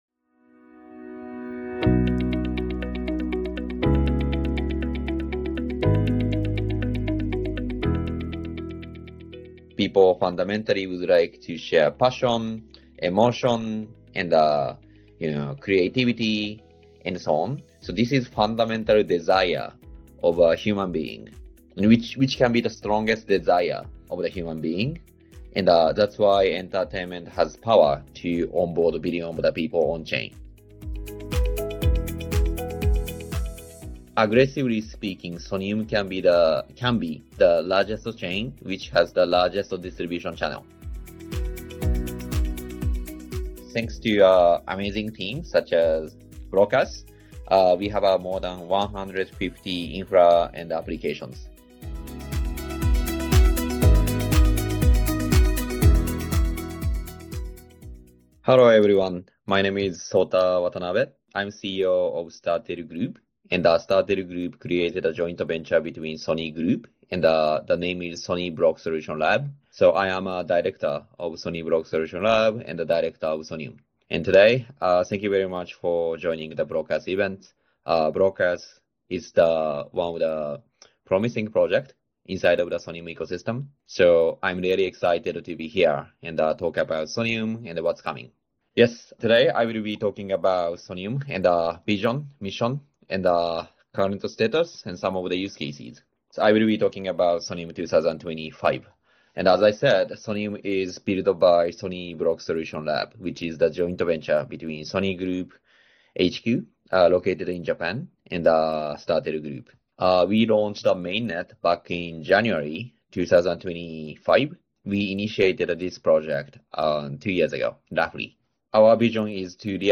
Live from NY: Pioneering Web3 Entertainment